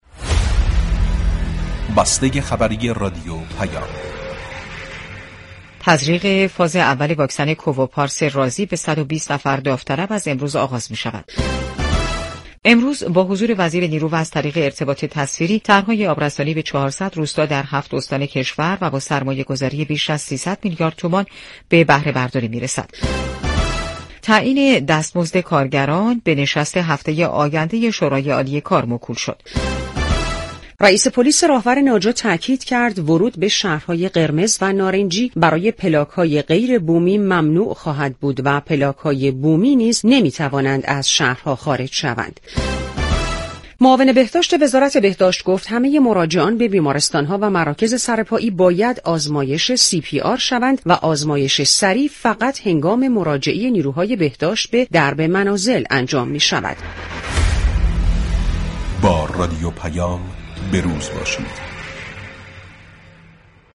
گزیده اخبار رادیو پیام در بسته ی خبری رادیو پیام.